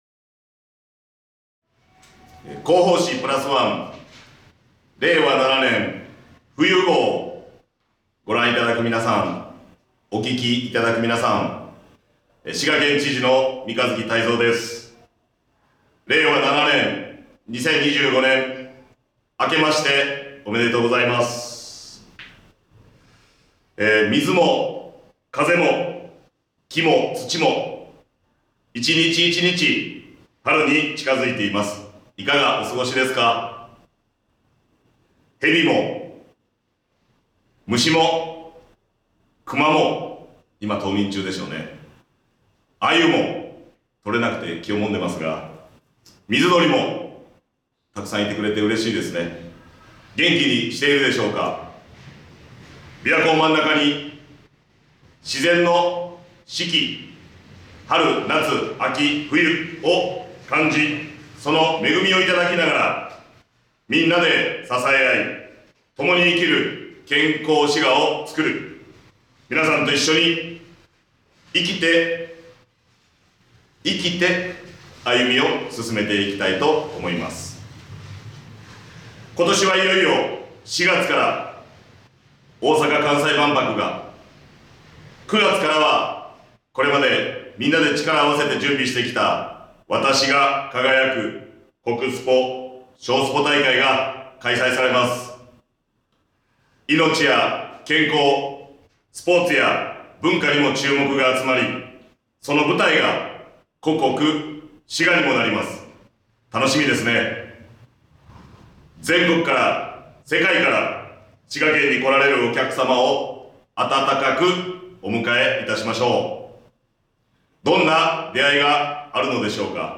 vol.50 【音声版】みんなでプラスワン（知事朗読）Vol.50 (mp3:8 MB) ほっとサロン 「滋賀プラスワン」冬号へのご感想や県政へのご意見などをお寄せいただいた方の中から抽選でプレゼントが当たります！